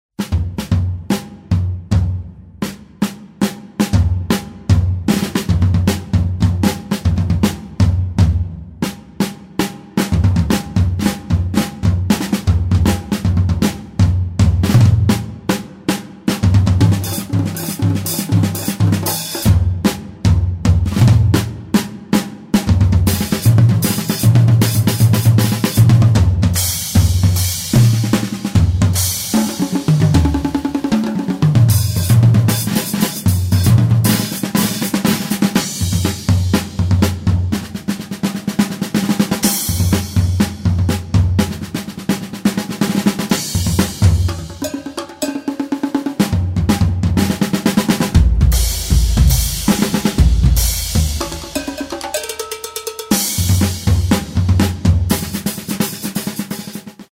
multiple-percussion duet